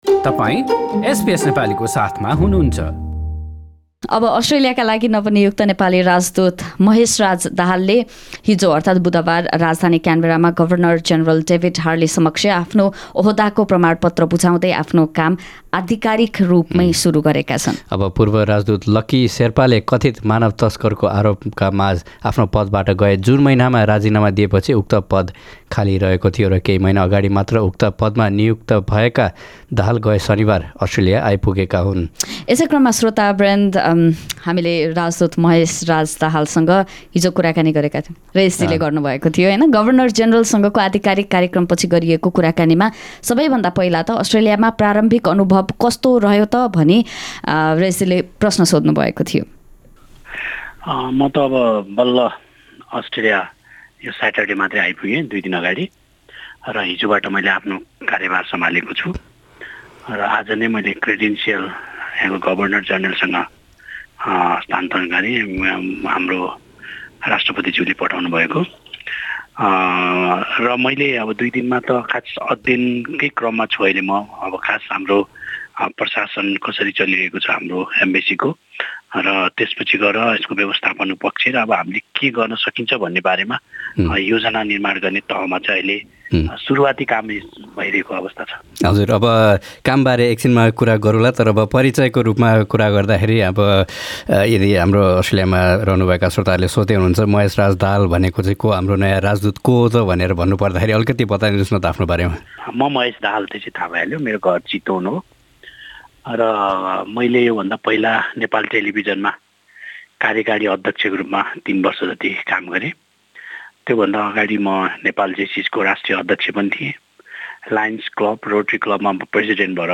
केहि महिना अगाडी मात्र अस्ट्रेलियाका लागि नेपाली राजदूत पदमा नियुक्त भएका महेशराज दाहालसँग हामीले हिजो कुराकानी गरेका थियौं।